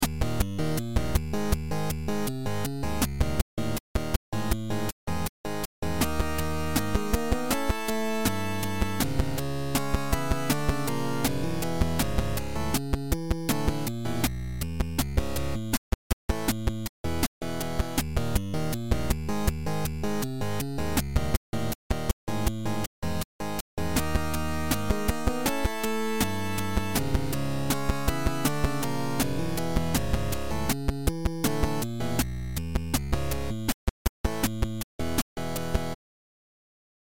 There is an occasional sour bass note, but otherwise I’m happy with the progress so far.
The melodies, parts, and arrangements were all formed procedurally.